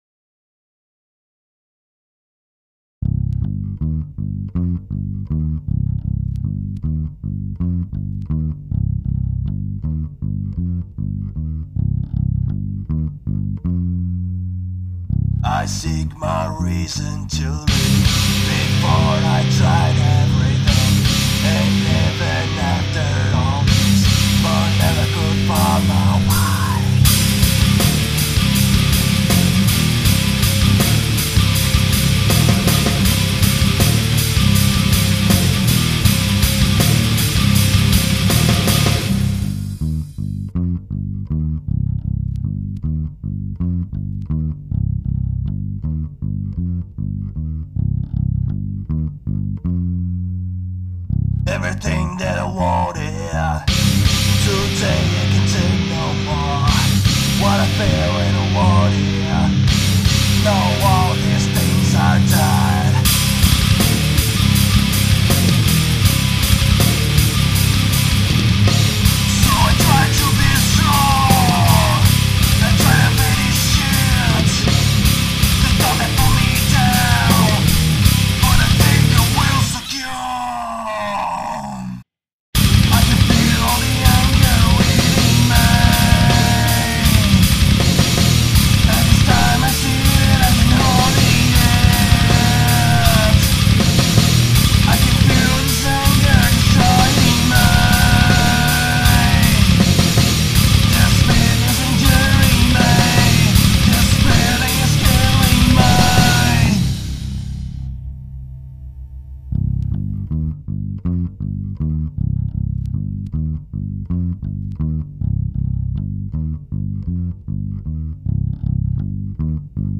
EstiloNew Metal